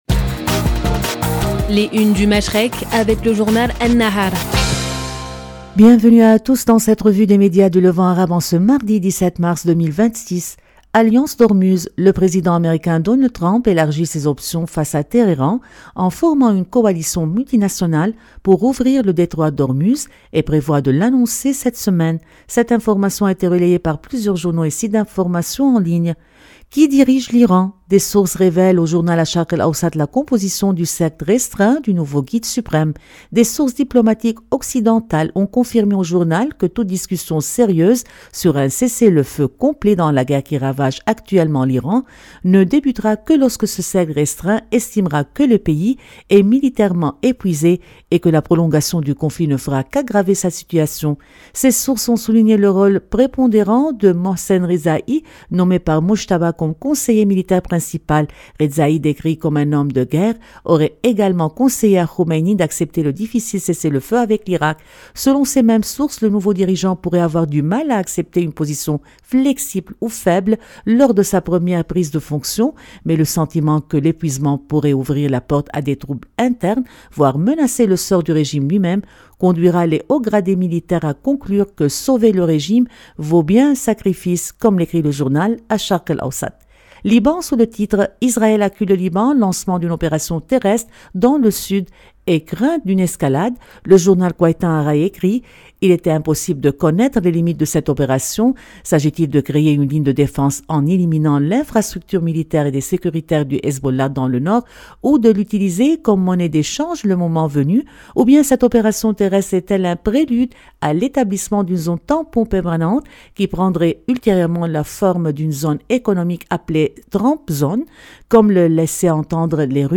Revue de presse des médias du Levant